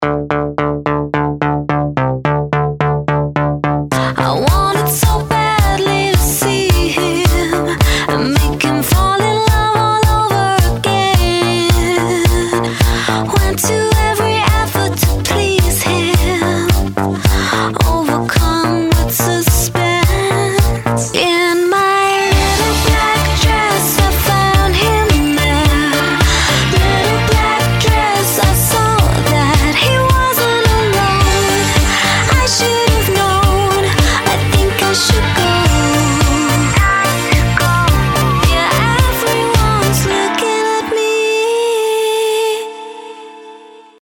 • Качество: 192, Stereo
женский вокал